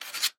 На этой странице вы найдете подборку звуков мела, пишущего по доске.
Шуршание мела по доске при быстром движении